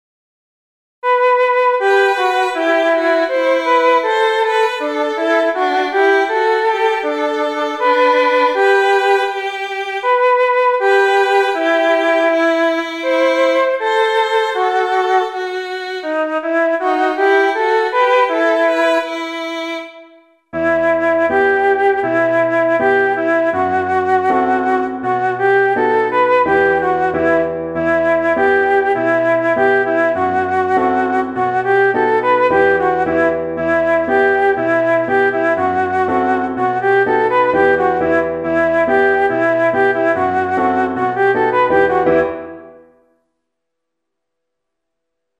Chant & Piano
Conte pour enfants